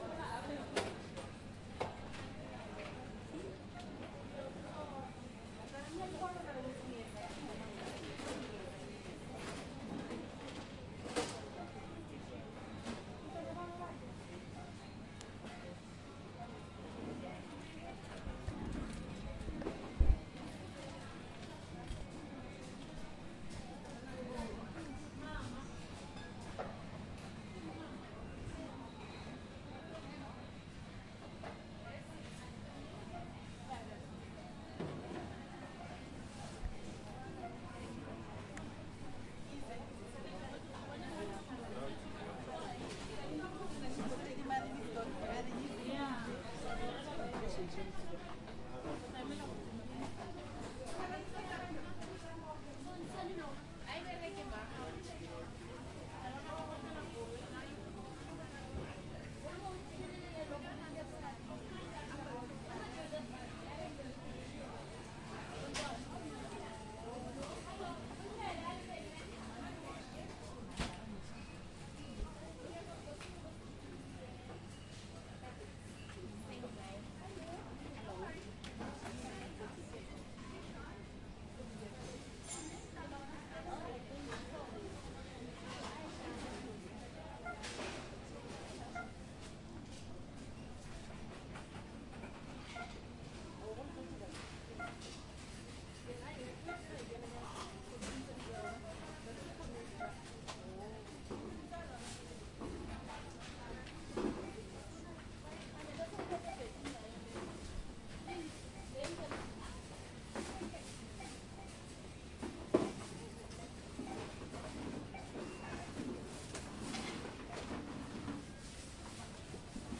描述：用Tascam DR40录制。记录在靠近结账点的超市。记录超市或商店环境。
Tag: 收银台 购物 氛围 环境 钱柜 超市 现金 氛围 硬币 购物中心的 OWI 杂货店 商店